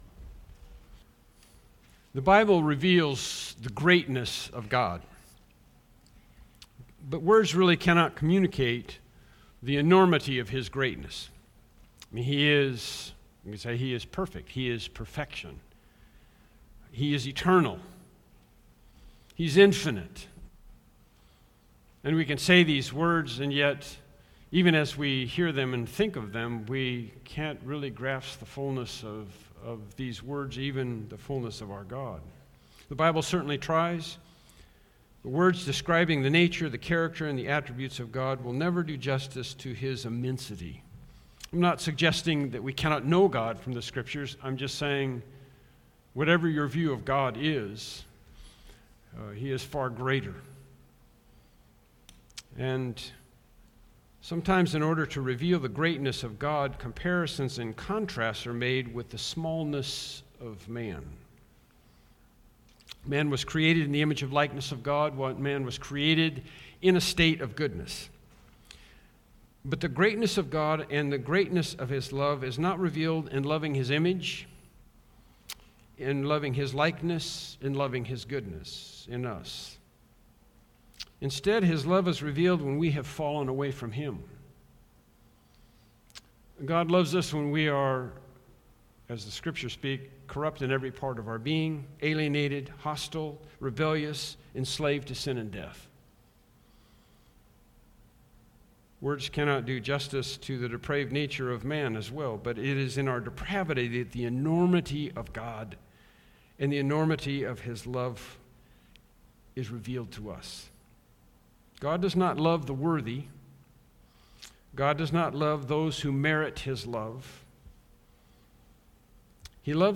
Series: Evening Sermons
Romans 5:6-11 Service Type: Evening Worship Service « Introduction to Jude “Contend for the Faith” »